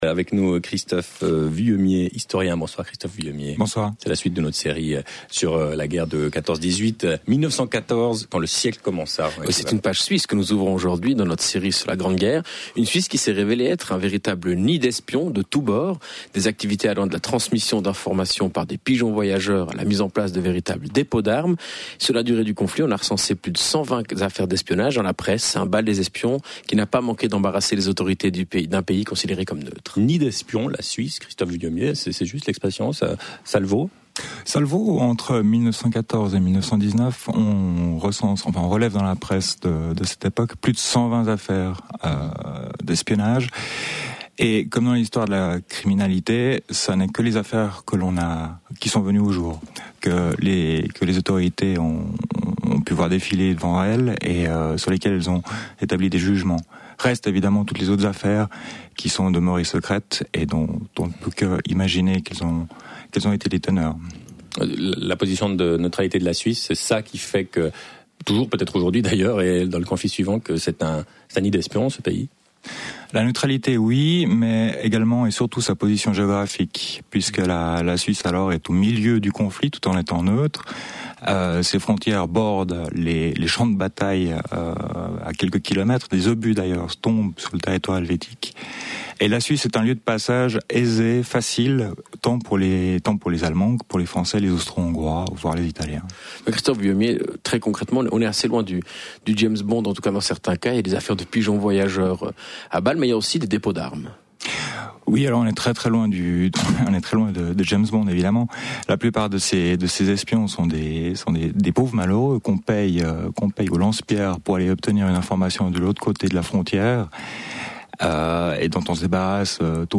Interview le 2 janvier 2014, dans l’émission Forum